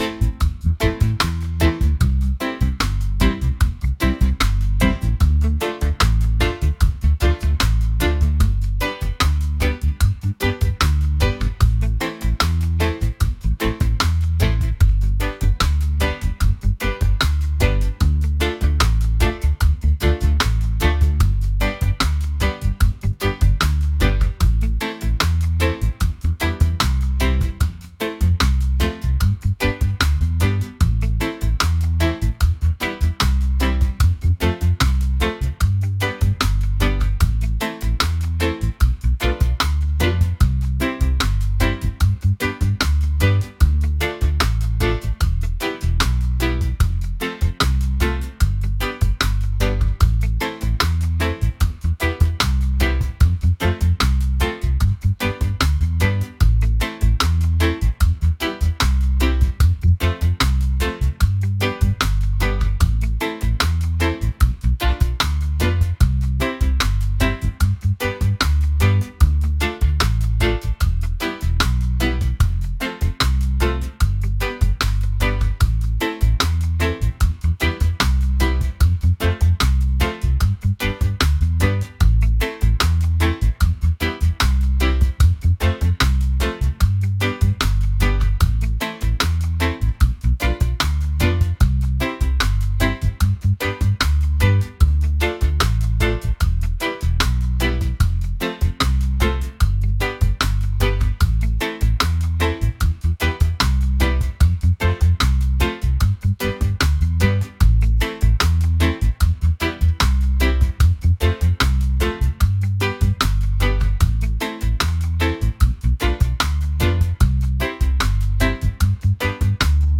laid-back | romantic | reggae